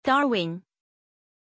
Pronunciation of Torwin
pronunciation-torwin-zh.mp3